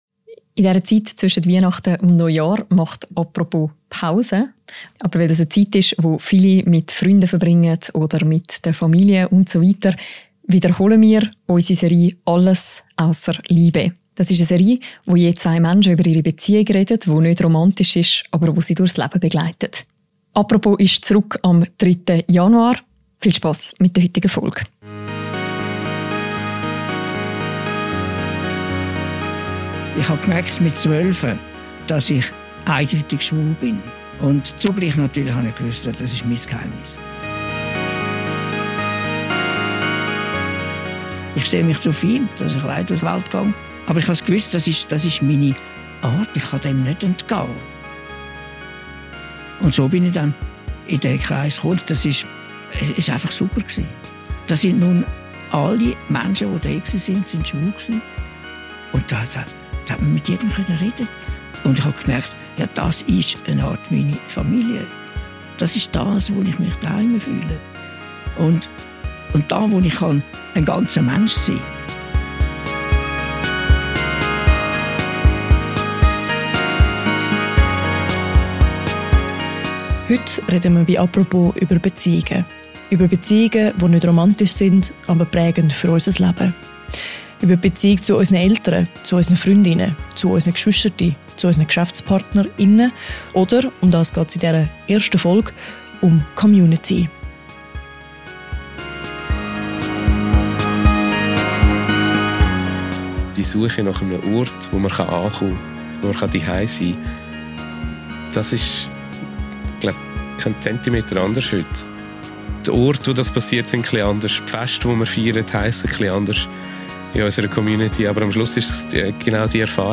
im Generationengespräch.